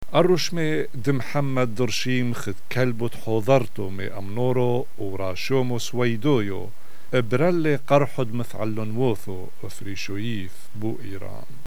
Here’s a recording of a news bulletin in a mystery language.
I’m guessing Albanian-there’s a voiceless dental fricative (th as in thin), which Persian doesn’t have.